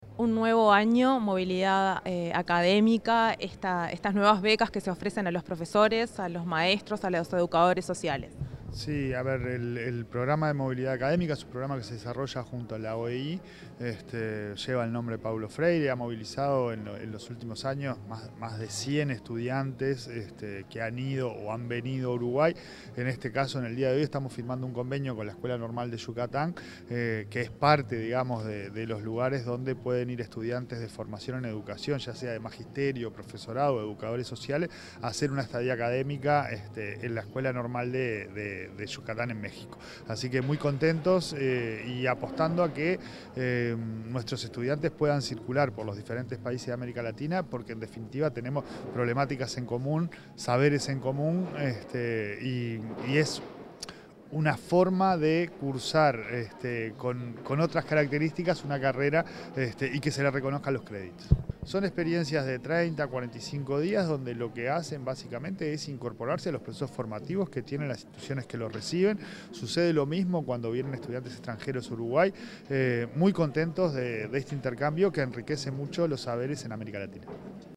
Declaraciones del presidente del Codicen de la ANEP, Pablo Caggiani
Declaraciones del presidente del Codicen de la ANEP, Pablo Caggiani 18/08/2025 Compartir Facebook X Copiar enlace WhatsApp LinkedIn Tras participar en el lanzamiento de una nueva edición del Programa de Movilidad Académica Paulo Freire, el presidente del Consejo Directivo Central (Codicen) de la Administración Nacional de Educación Pública (ANEP), Pablo Caggiani, dialogó con la prensa.